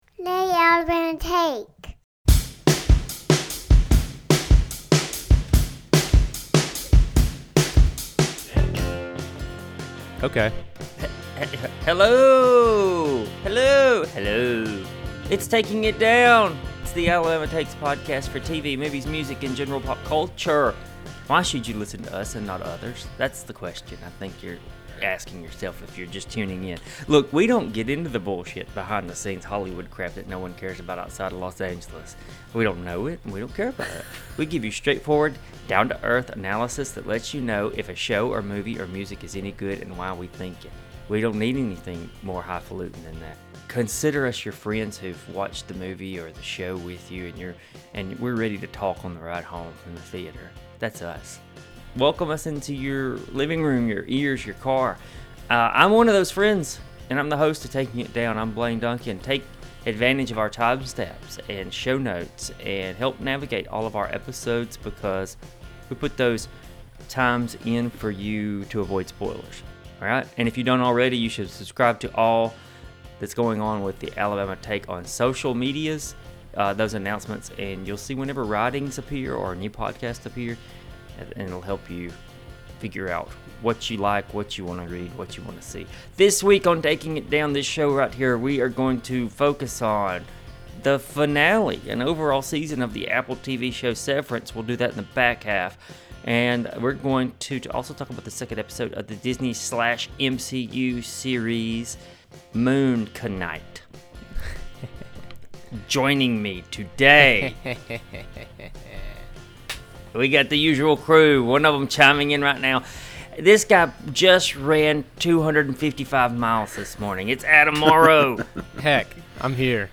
and all three hosts then talk about the second episode of the Disney+ series Moon Knight